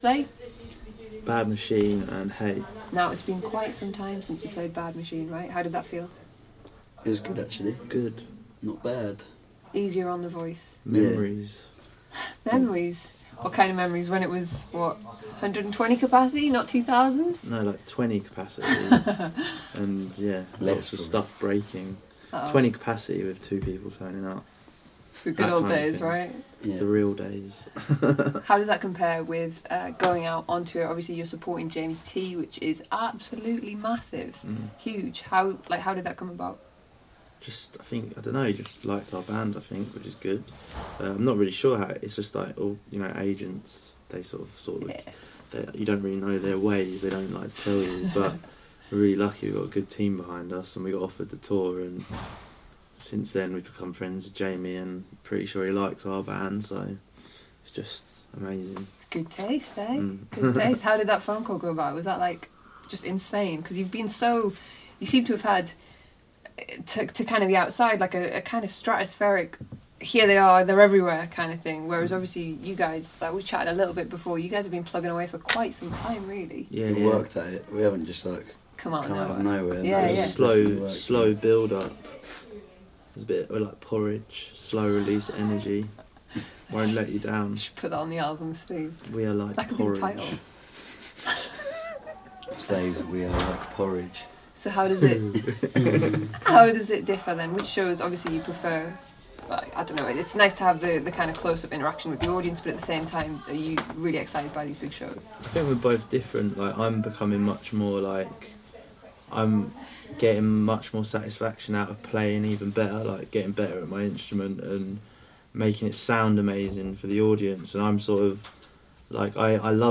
Tips For 2015 - Slaves - Interview Part 1